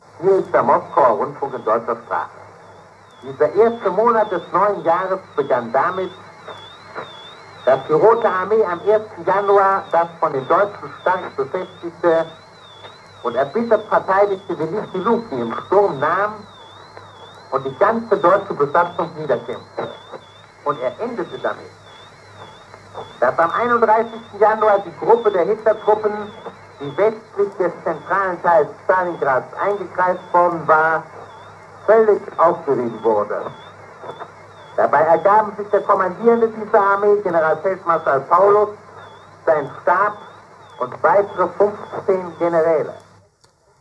Februar 1943, Nachrichten u.a. zur Kapitulation von Generalfeldmarschall Paulus